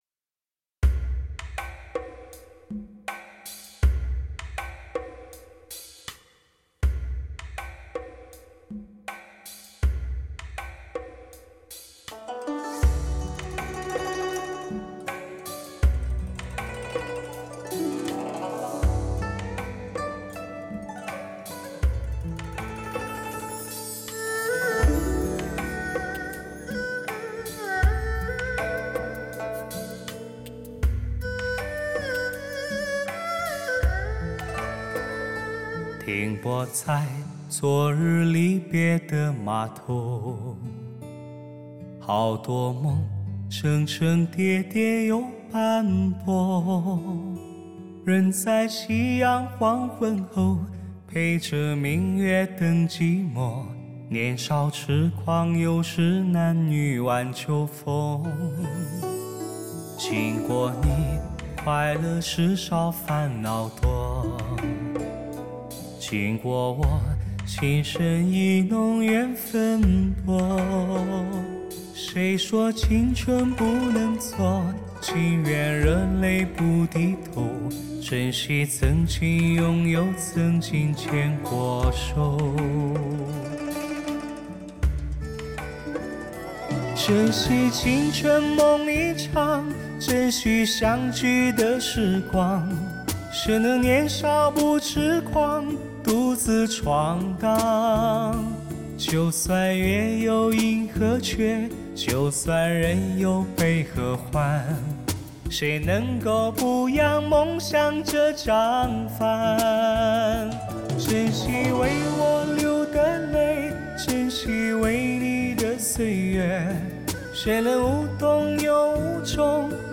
完美环绕+最新科技DTS-ES6.1，还原最真实的HI-FI声场效果营造最感人的真情氛围。